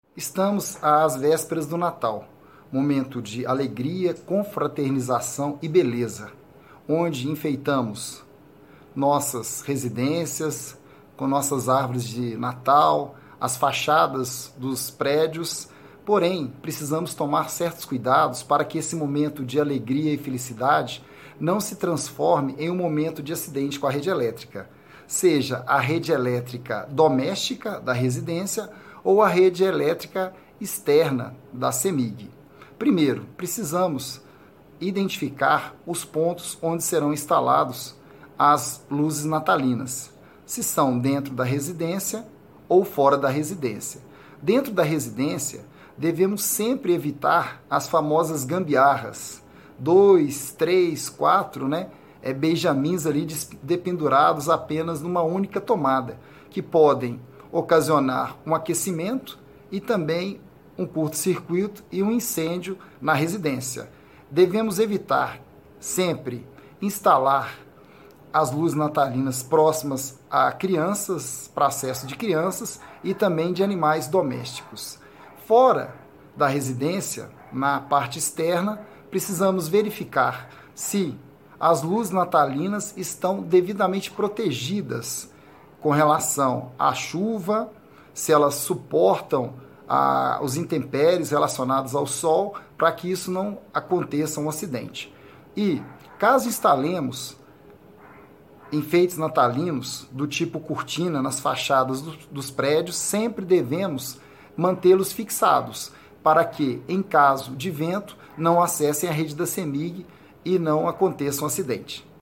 Ouça a Sonora